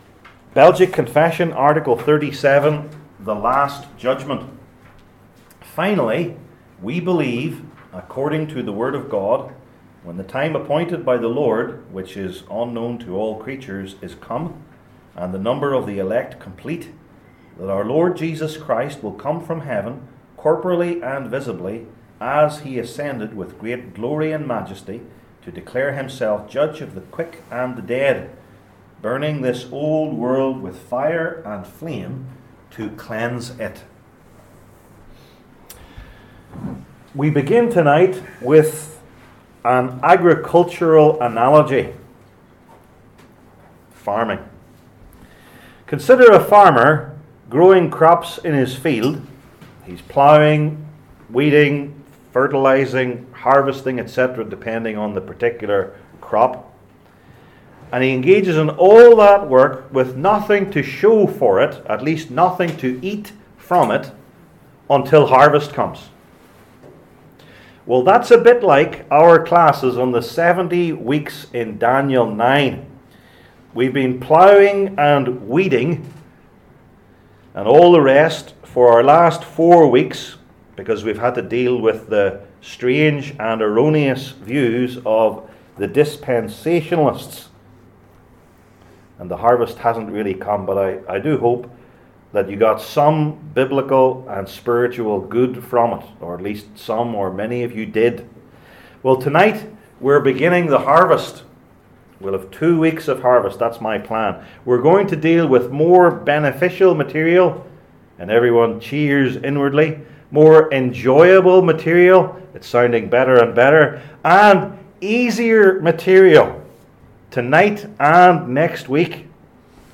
Passage: Daniel 9:3-27 Service Type: Belgic Confession Classes